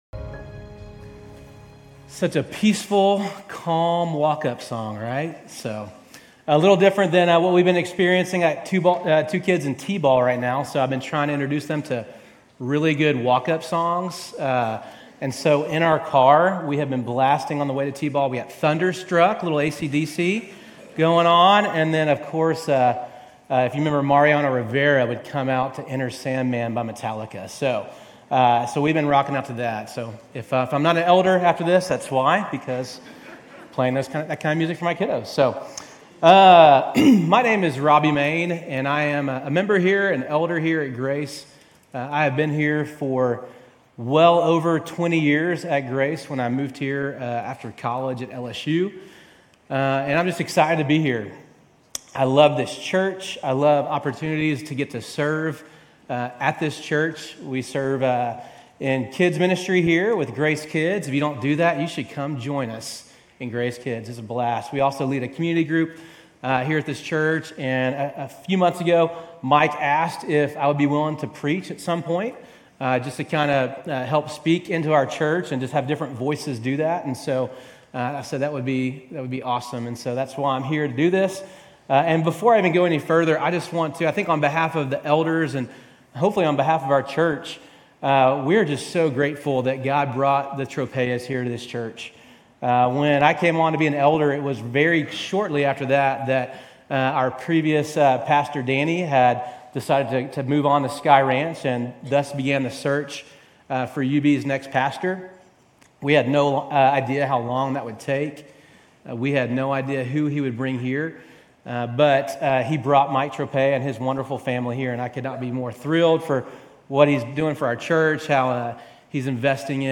Sermons Galatians 3:15-29, 4:1-7 The Law and the Promise